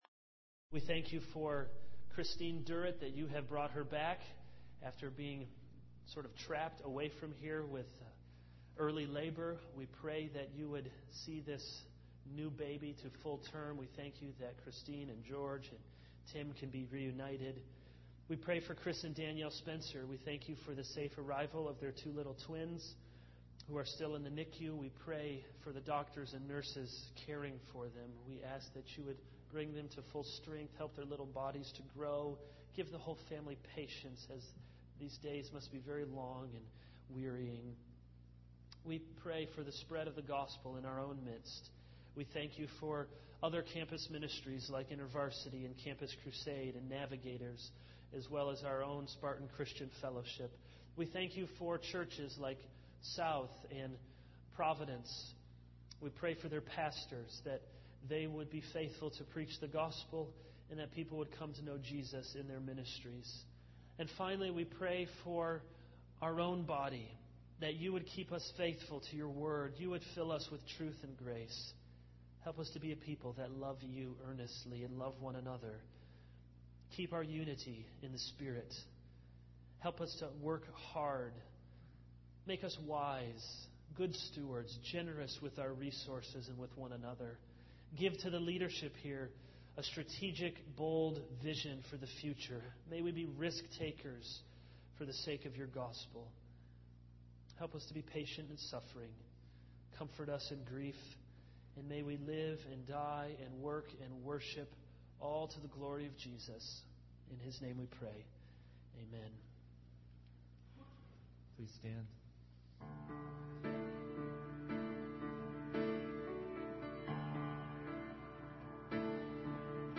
All Sermons Loving God 0:00 / Download Copied!